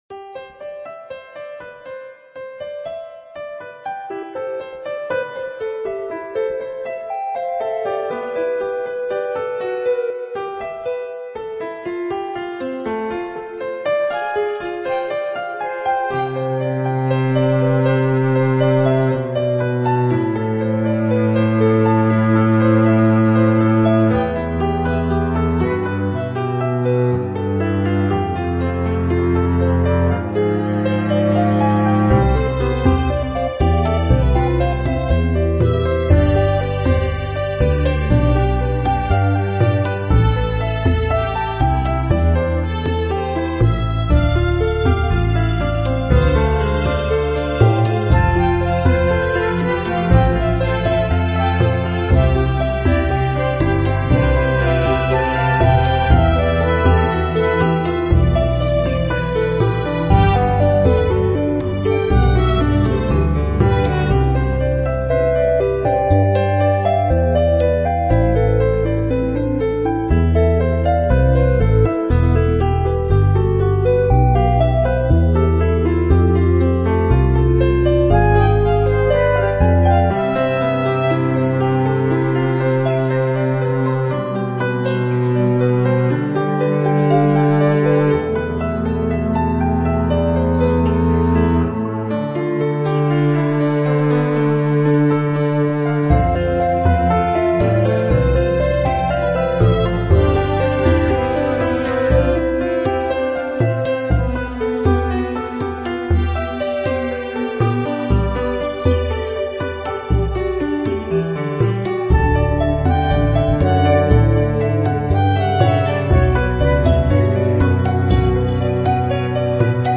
Relaxed Soundtrack Music with Piano and Flute lead